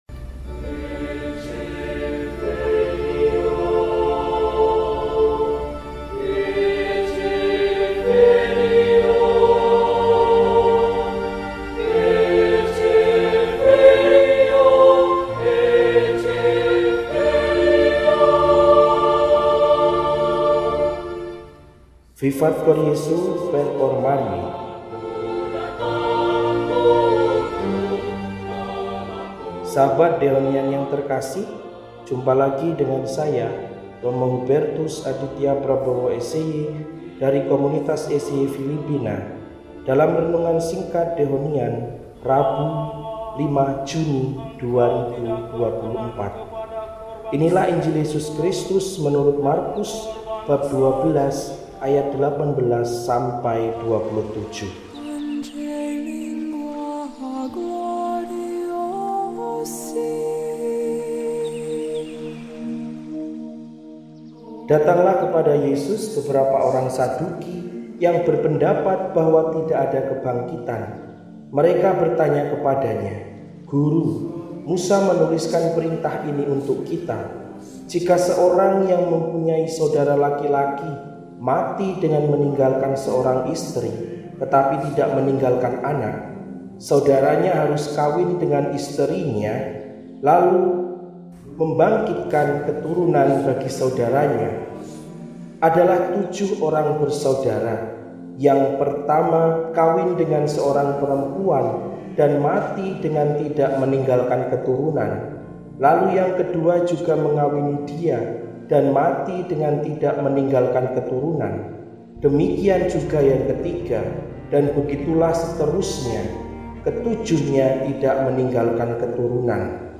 St. Bonifasius Usk.Mrt – RESI (Renungan Singkat) DEHONIAN